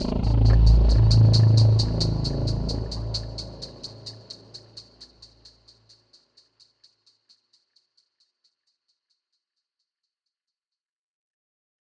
Bass_A_03.wav